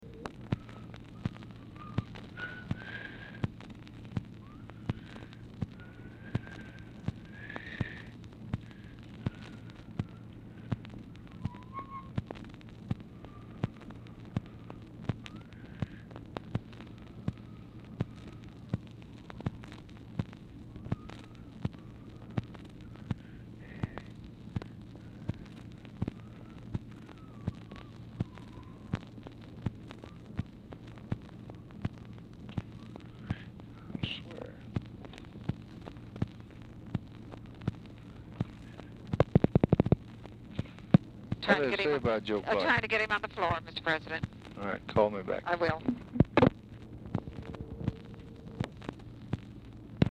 Telephone conversation
LBJ WHISTLES WHILE WAITING
Format Dictation belt
Speaker 2 TELEPHONE OPERATOR